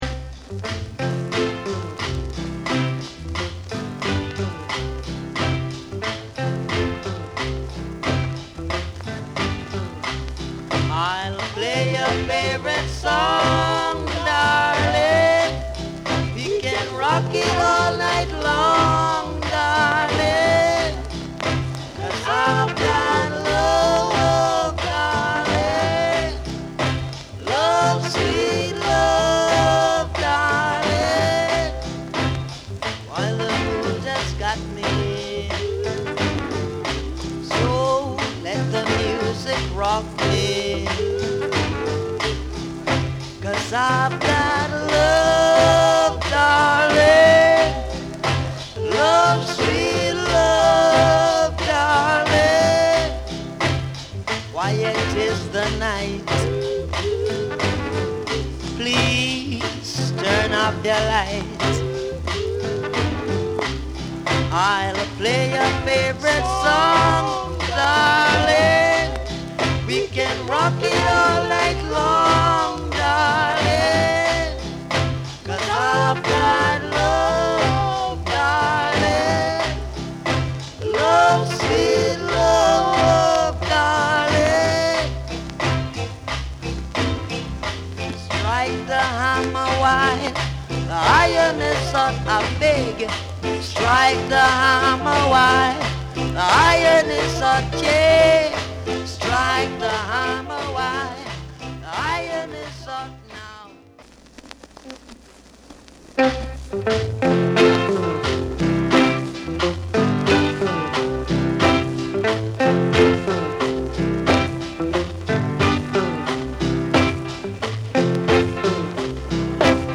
Genre: Rocksteady
落ち着いたリズムと繊細なコーラスワークが持ち味。
B面はダブ・バージョン。